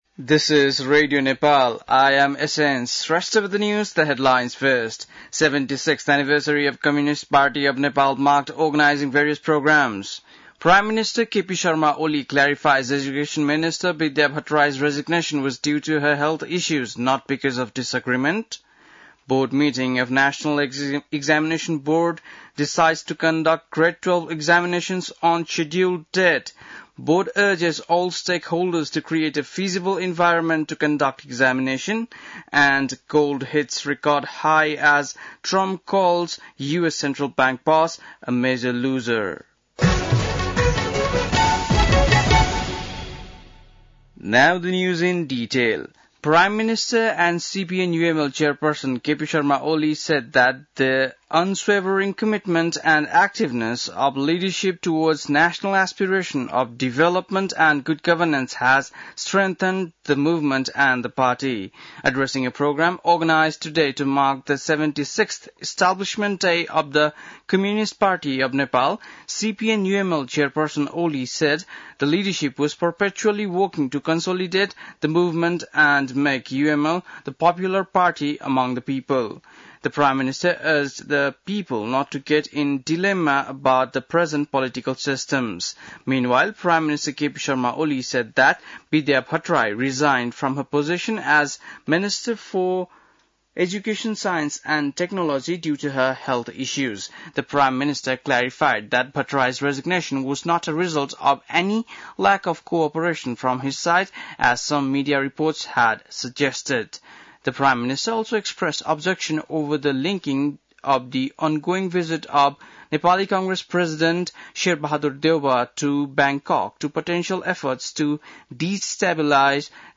बेलुकी ८ बजेको अङ्ग्रेजी समाचार : ९ वैशाख , २०८२
8-pm-english-news-3.mp3